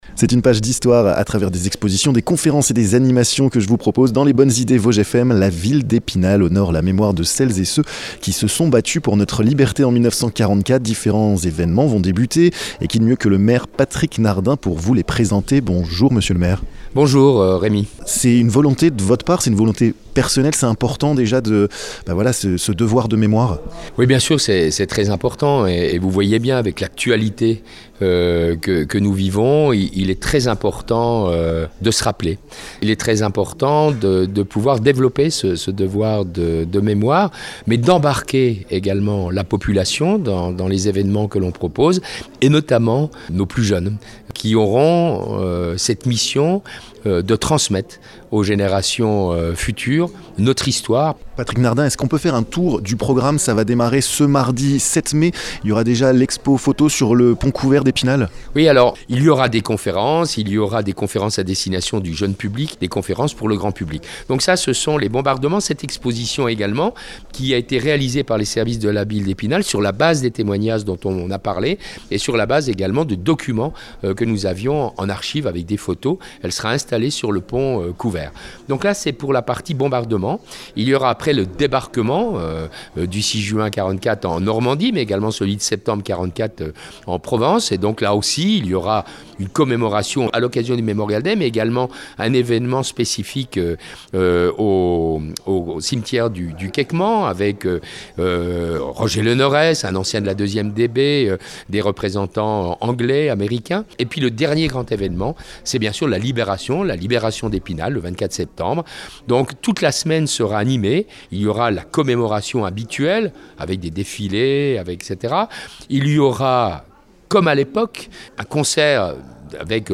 Des expositions photos, des conférences, l'inauguration d'une plaque commémorative, des diffusions sonores dans le centre-ville des récits des bombardements de 1944, ... jusqu'en septembre, fêtez la libération d'Epinal. Patrick Nardin, maire de la ville, vous présente les différents événements dans ce podcast.